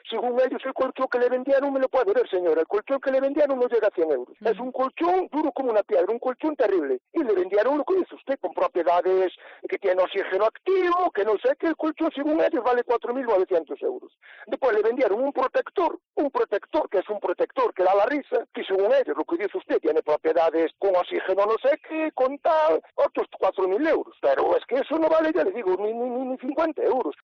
Testimonio del familiar de una víctima de estafa a domicilio